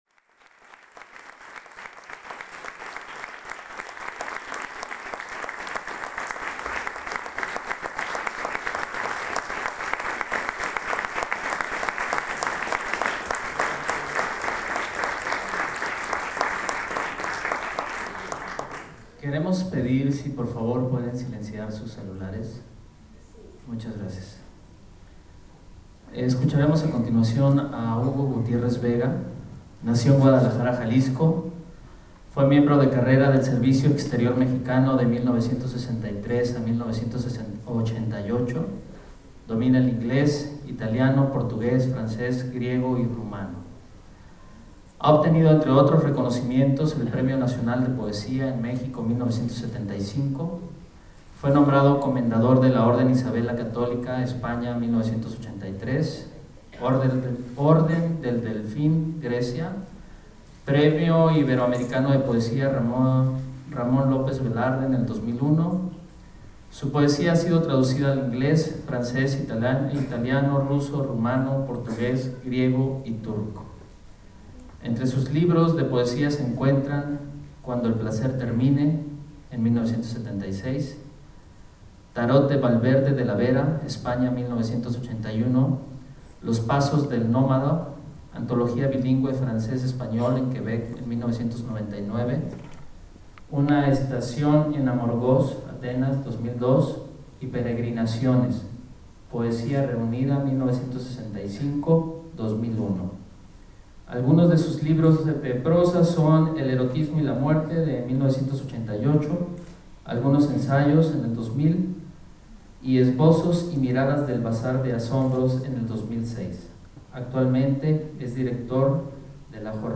En voz de Hugo Gutiérrez Vega
Grabación realizada el 28 de octubre de 2008 en el Auditorio del Centro Cultural de Chiapas Jaime Sabines en Tuxtla Gutiérrez Chiapas México Regresar al índice principal | Acerca de Archivosonoro